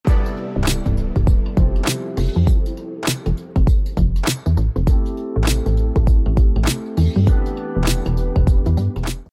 Royalty Free Music